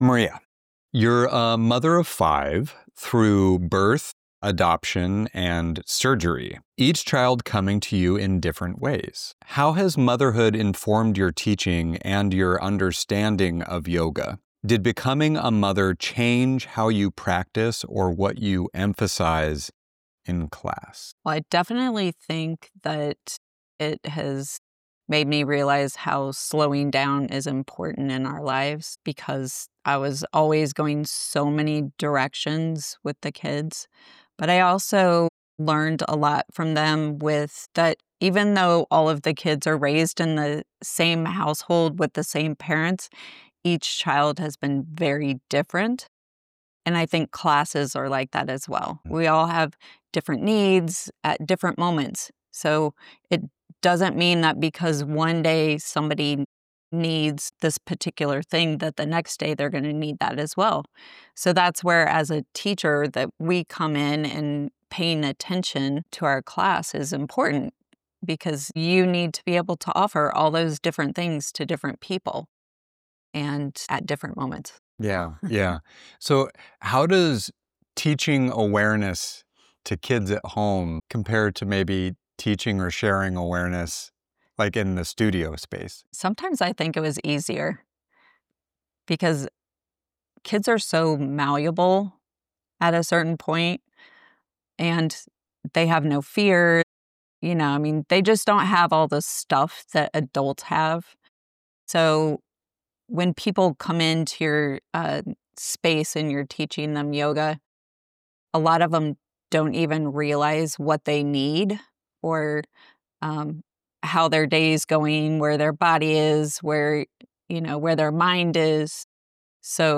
A gentle conversation about accessible yoga, motherhood's wisdom, and permission to pause.